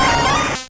Cri de Boustiflor dans Pokémon Rouge et Bleu.